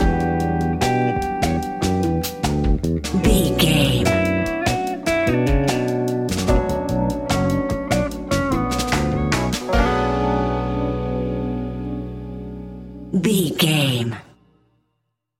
Fast paced
In-crescendo
Uplifting
Ionian/Major
B♭
hip hop